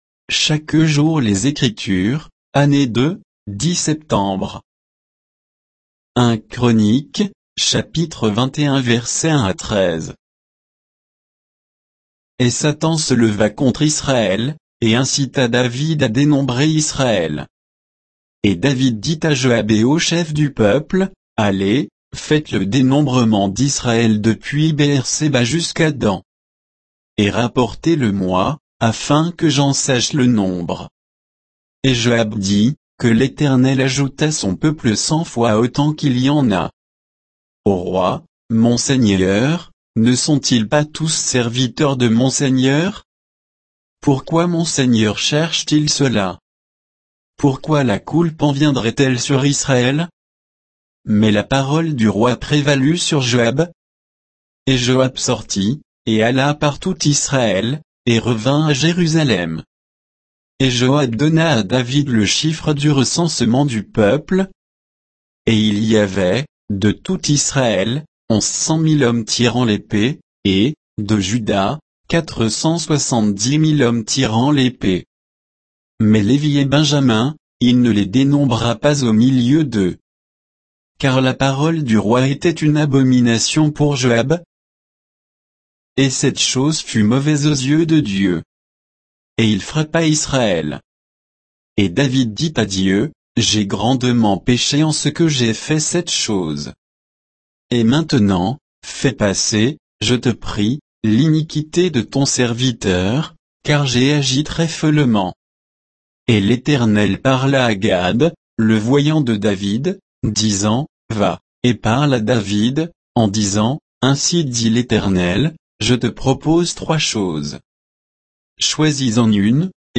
Méditation quoditienne de Chaque jour les Écritures sur 1 Chroniques 21